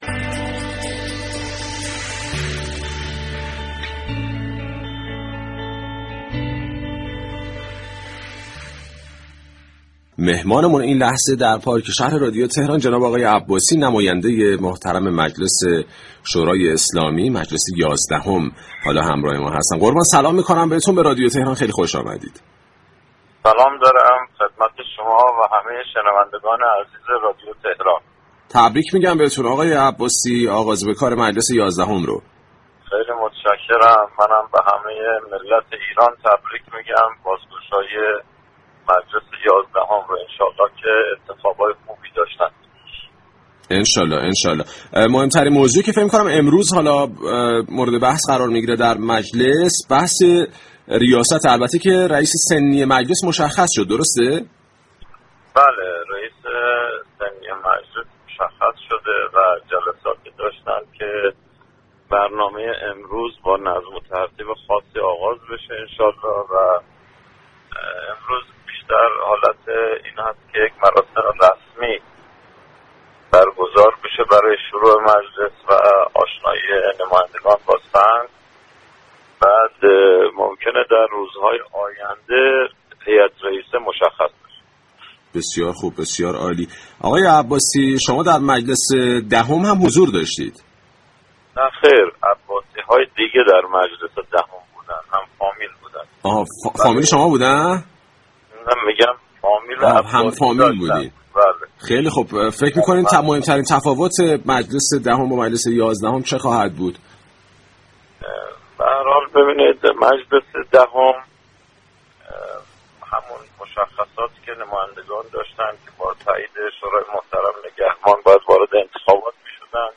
فریدون عباسی در گفتگو با "پارك‌شهر" بر این مساله تاكید كرد: رسانه‌های كشور نسبت به جریان انتخاب مجلس طوری رفتار می‌كنند كه به جز، آقایان قالیباف و حاجی بابایی، گزینه‌ی دیگری برای ریاست دوره‌ی یازدهم مجلس شورای اسلامی در اذهان مردم كشورمان مطرح نباشد.